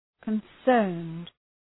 Shkrimi fonetik {kən’sɜ:rnd}